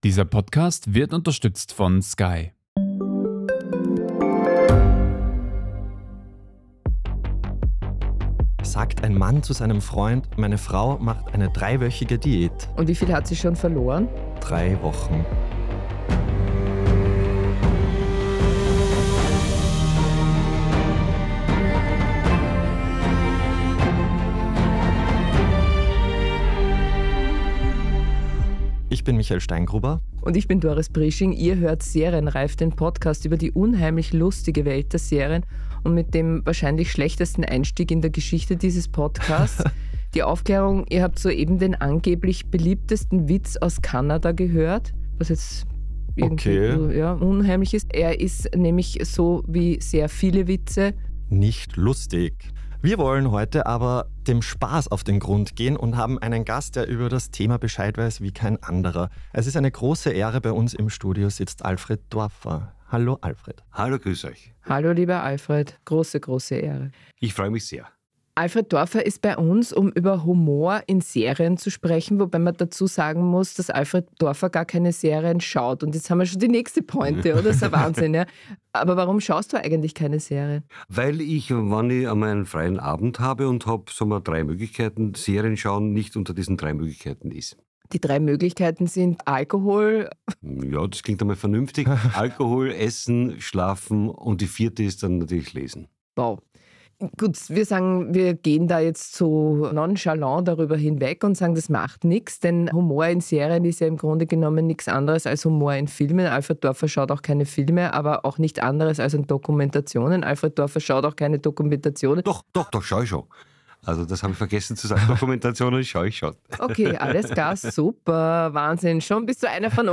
Hier wird besprochen, was die Serientäter- und täterinnen von DER STANDARD gerne sehen, geben Orientierung im Seriendschungel und diskutieren mit Experten und Expertinnen über die Hintergründe und bewegenden Themen aktueller Produktionen von Netflix, Amazon, HBO, Sky und Co. Moderation & Redaktion